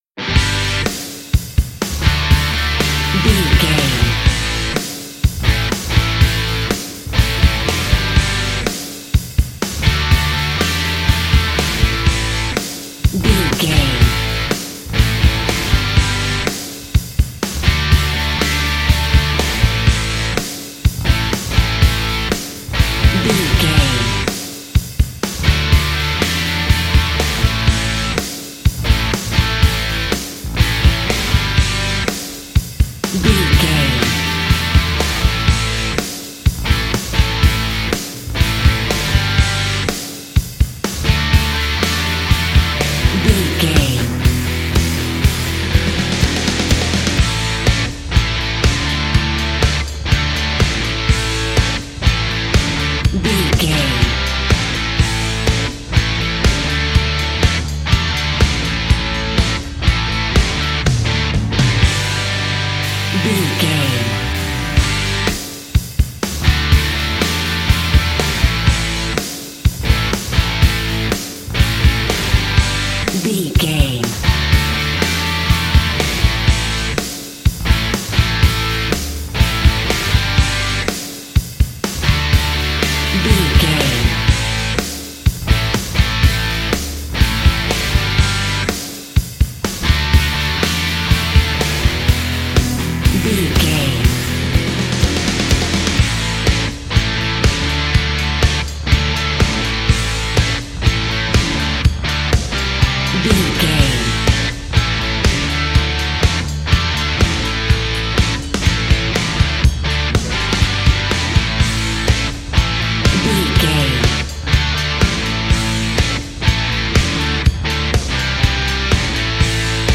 Ionian/Major
electric guitar
drums
bass guitar
pop rock
hard rock
lead guitar
aggressive
energetic
intense
powerful
nu metal
alternative metal